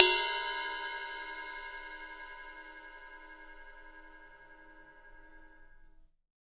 RIDE20HV B.1.wav